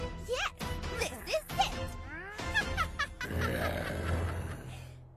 Animation
Home Studio, Rode NT1 Kondensatormikrofon der 5. Generation